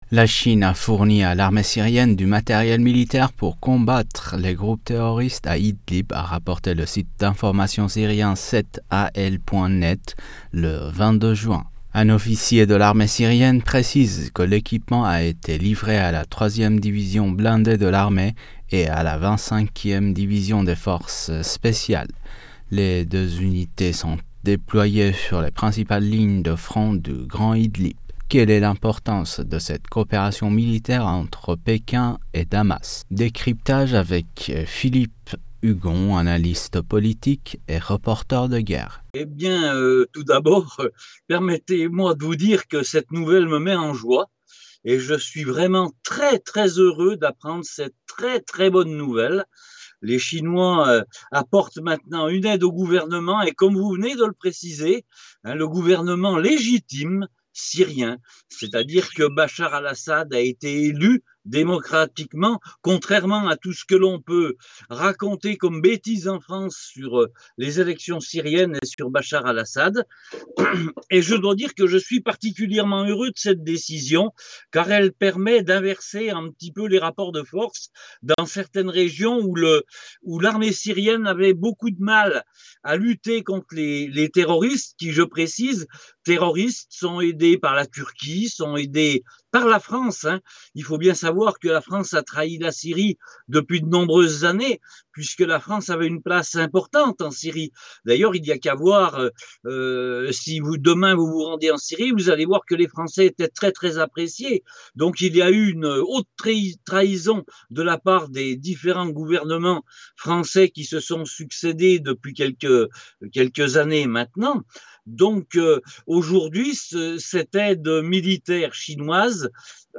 reporter de guerre s'exprime sur le sujet.